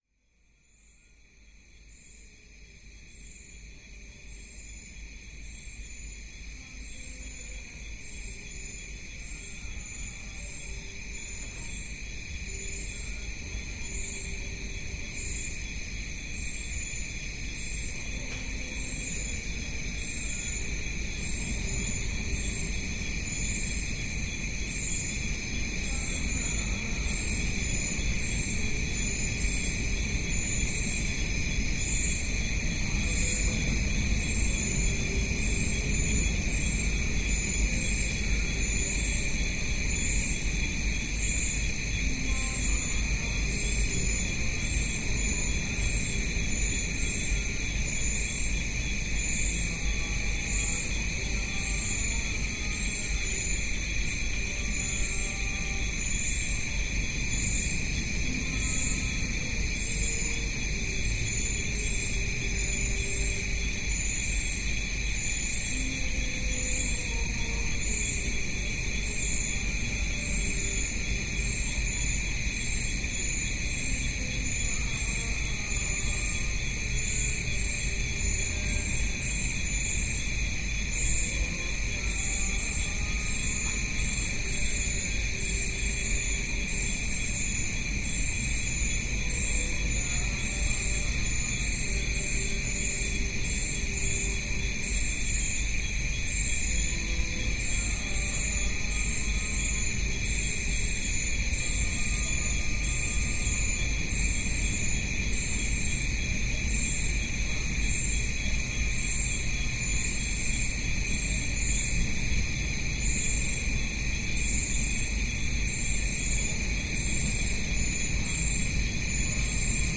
Unawatuna, Sri Lanka - insects and mysterious drones